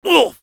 Voice file from Team Fortress 2 French version.
Category:Soldier audio responses/fr You cannot overwrite this file.
Soldier_painsharp01_fr.wav